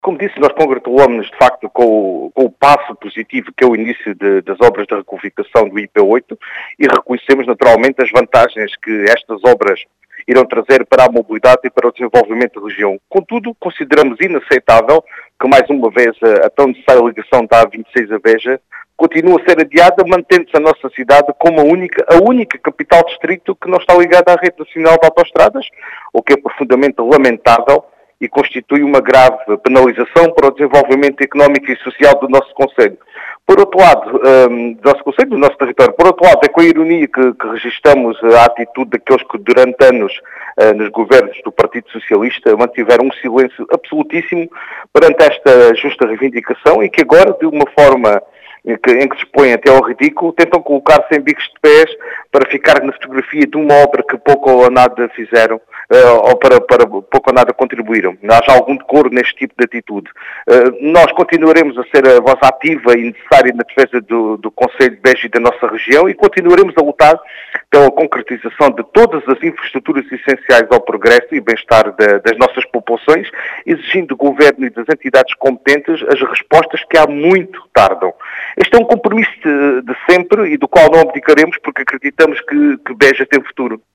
As explicações são de Vítor Picado, vereador da CDU na  Câmara Municipal de Beja.